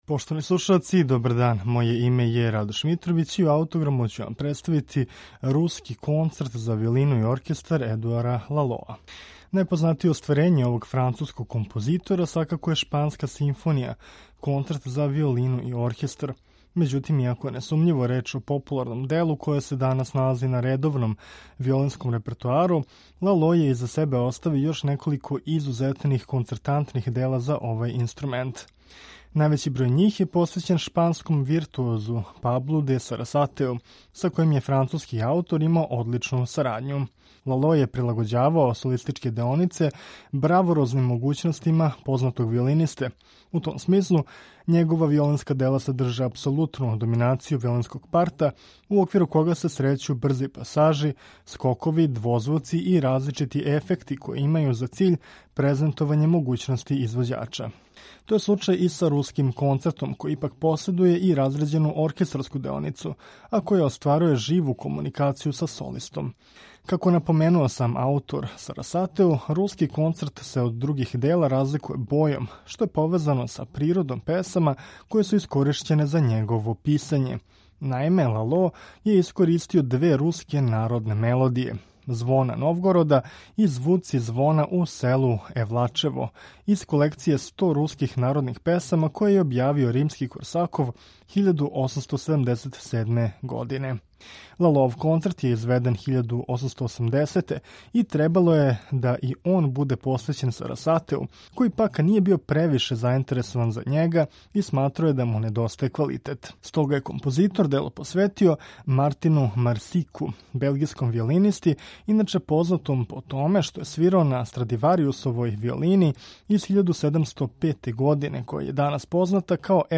Написан 1880, овај концерт је заснован на мелодијама двеју руских песама и садржи изузетно виртуозну солистичку деоницу.